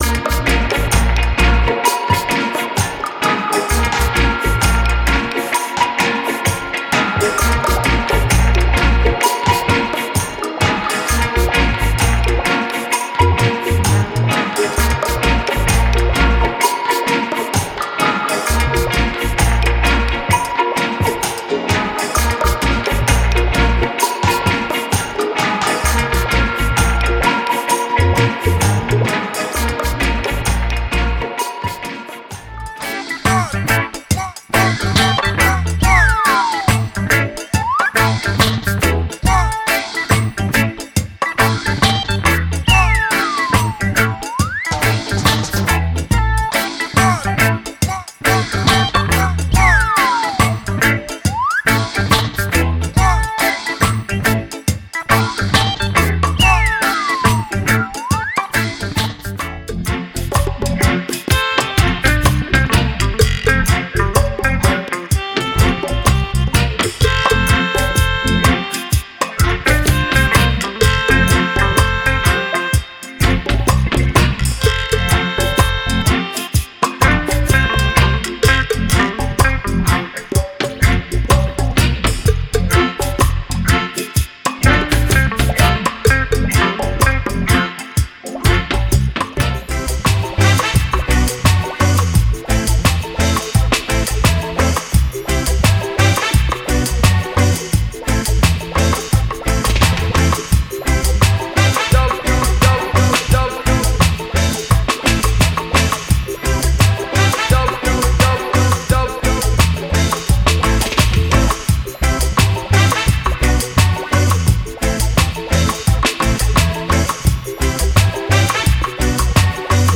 Genre:Dub
重厚なベースラインが土台を支え、スカンクとパーカッションがグルーヴに命を吹き込みます。
デモサウンドはコチラ↓